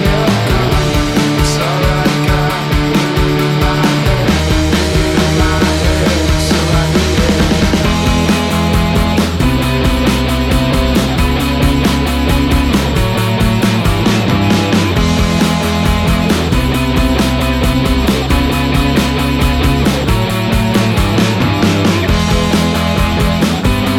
no Backing Vocals Rock 3:58 Buy £1.50